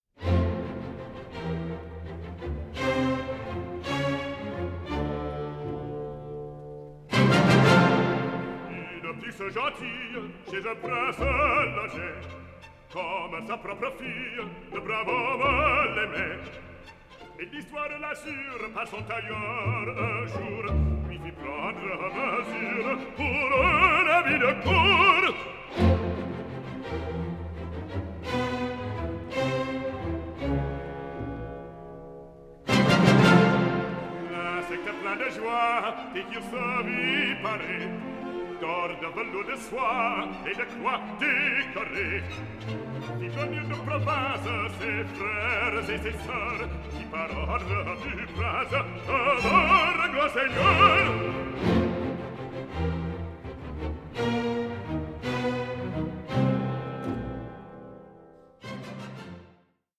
Légende dramatique en quatre parties
soprano
tenor
bass